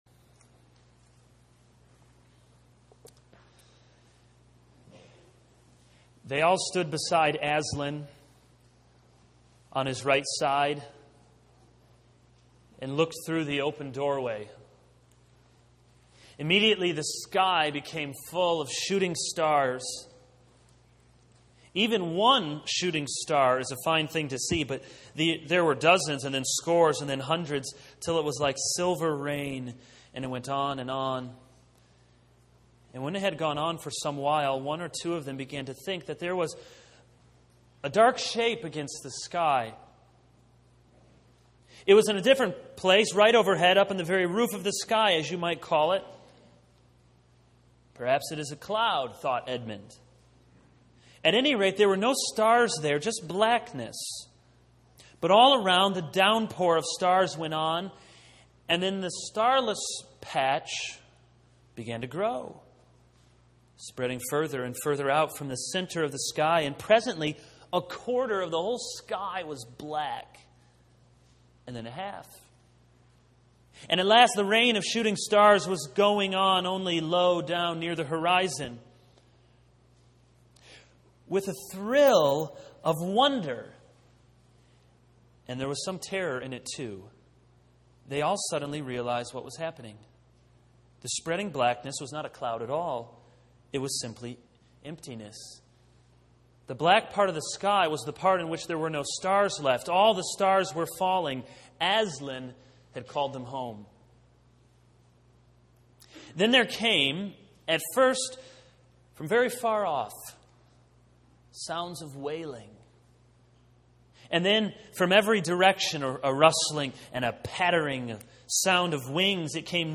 This is a sermon on 2 Corinthians 5:6-10.